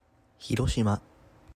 Hiroshima (広島市, Hiroshima-shi, /ˌhɪrˈʃmə/, also UK: /hɪˈrɒʃɪmə/,[2] US: /hɪˈrʃɪmə/, [çiɾoɕima]